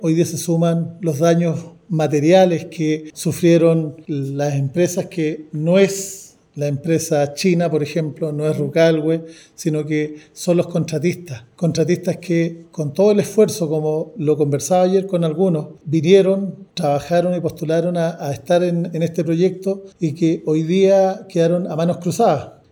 En conversación con Radio Bío Bío, el jefe comunal manifestó su preocupación por el daño a la imagen de la comuna y la confianza en los emprendedores e inversionistas.